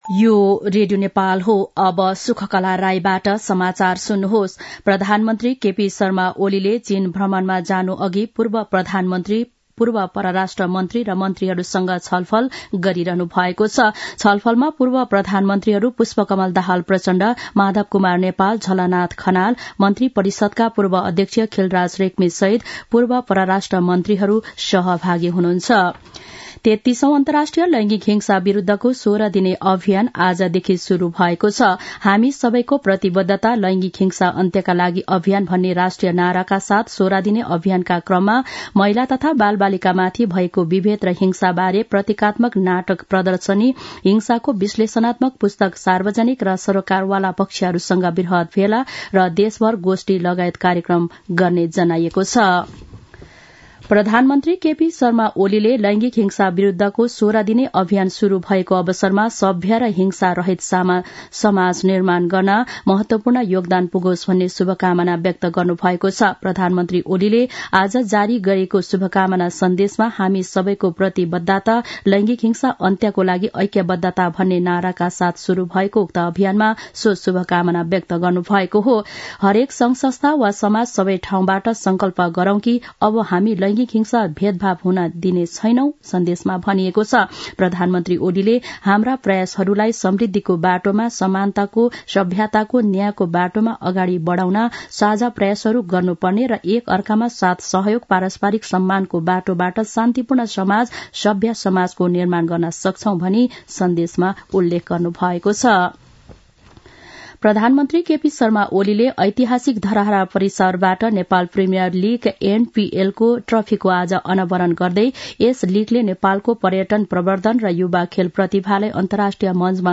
दिउँसो १ बजेको नेपाली समाचार : ११ मंसिर , २०८१
1-pm-nepali-news-1-7.mp3